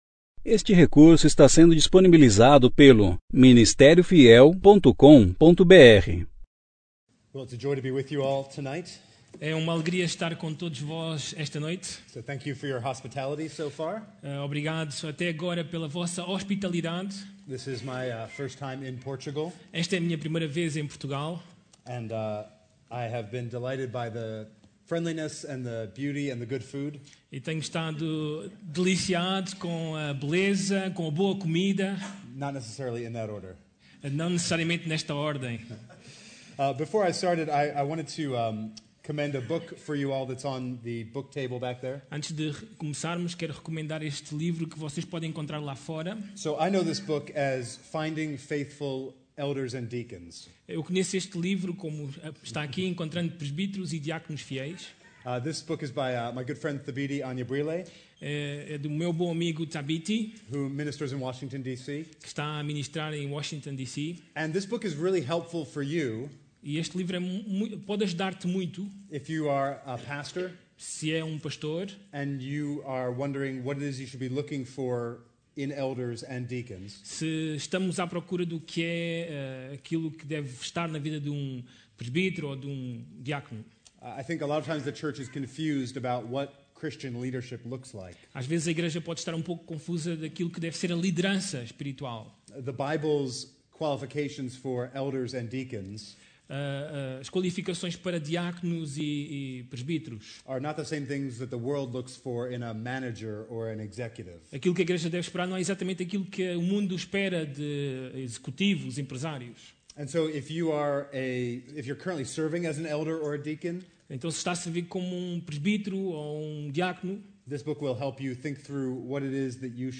Conferência: 16ª Conferência Fiel para Pastores e Líderes – Portugal Tema: Reedifiquem esta casa!